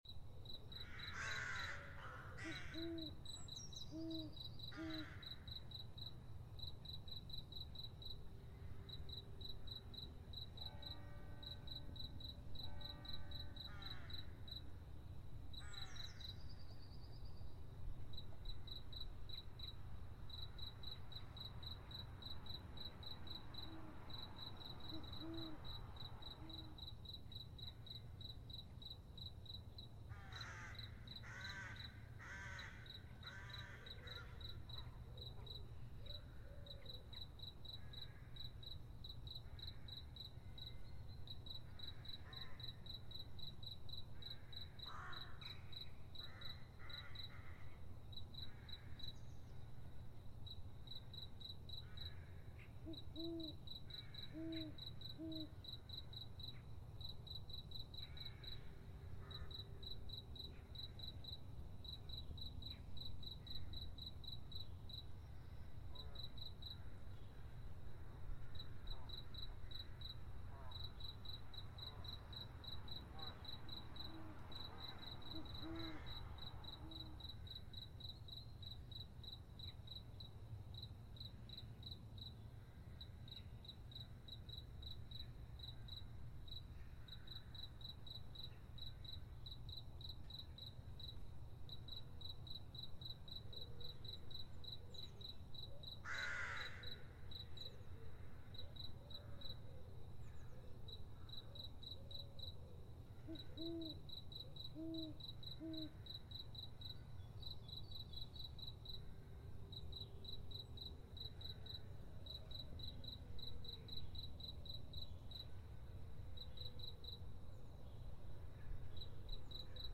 NightAmbience.wav